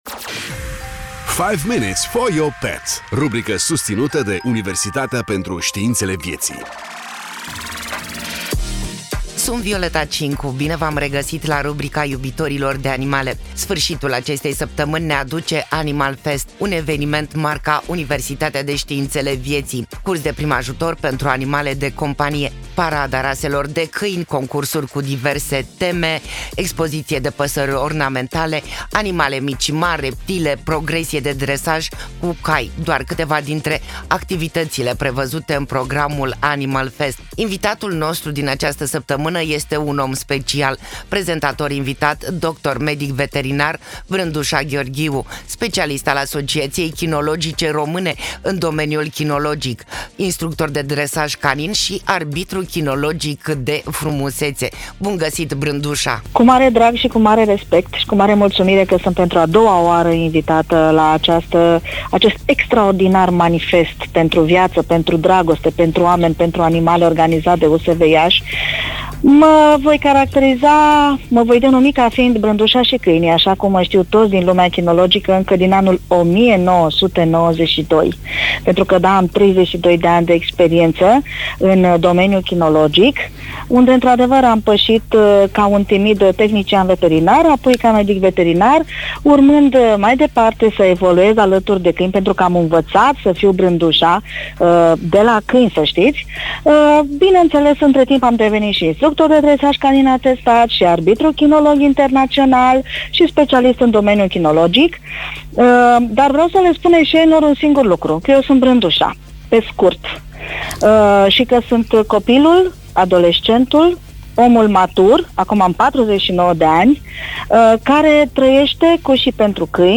Bine v-am regăsit la rubrica iubitorilor de animale.